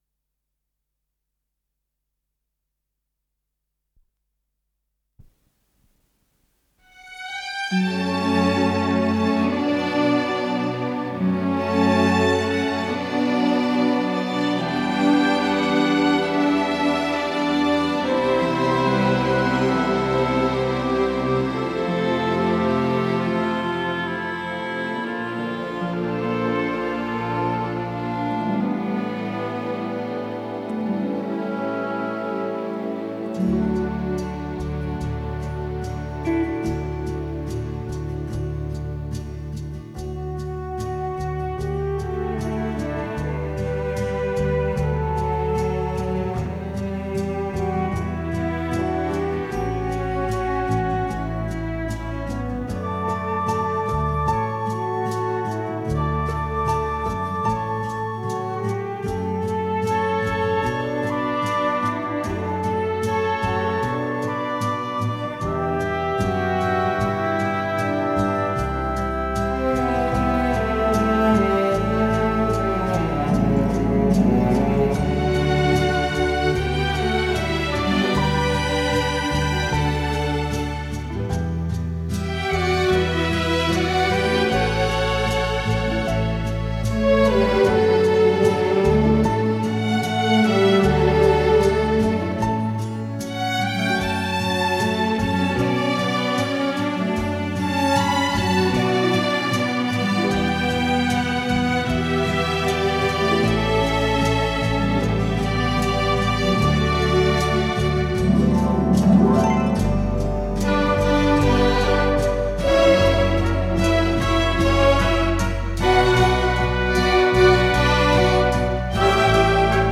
с профессиональной магнитной ленты
ре мажор